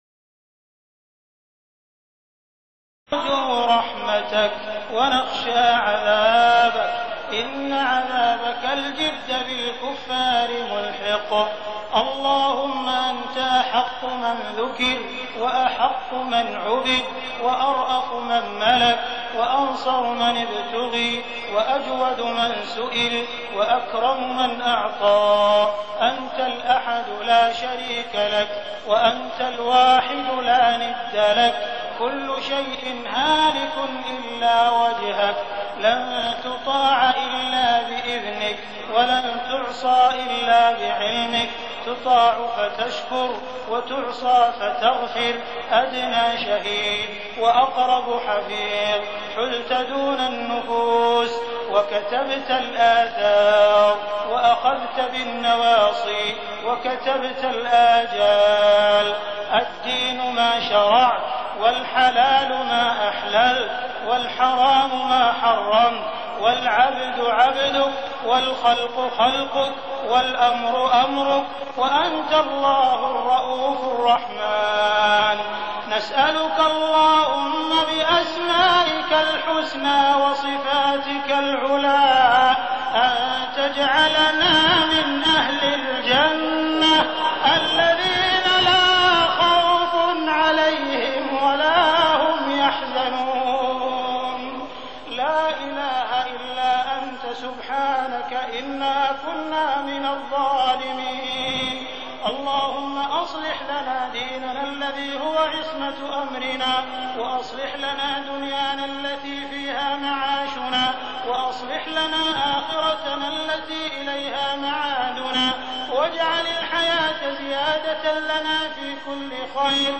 دعاء القنوت رمضان 1419هـ > تراويح الحرم المكي عام 1419 🕋 > التراويح - تلاوات الحرمين